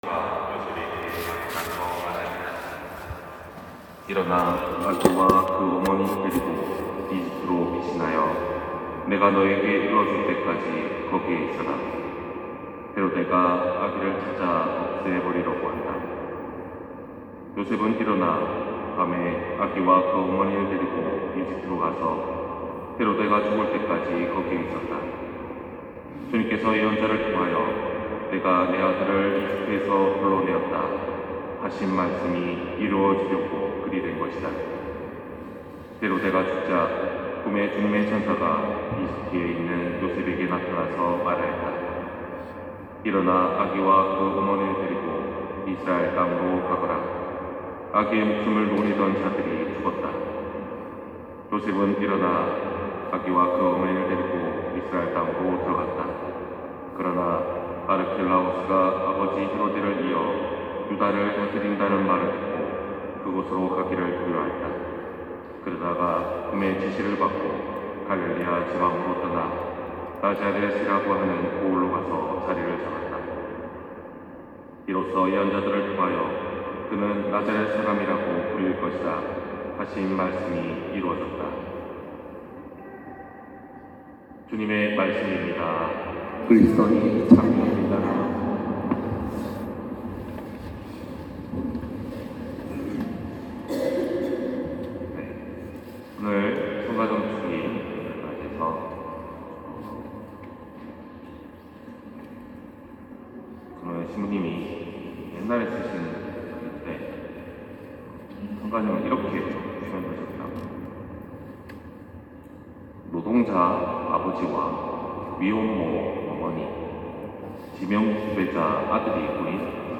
251227 신부님 강론말씀